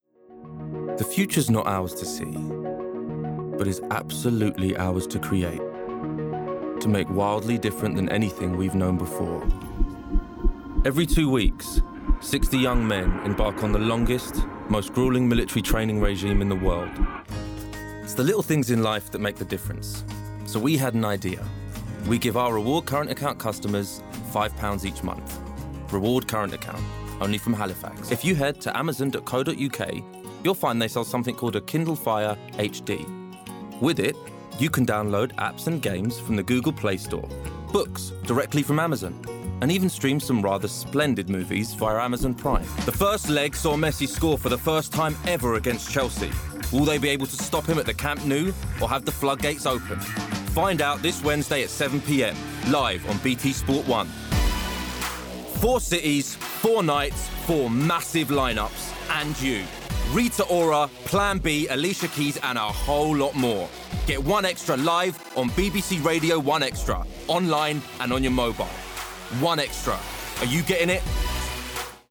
Commercial Showreel
London, RP ('Received Pronunciation'), Straight
Commercial, Cool, Edgy, Smooth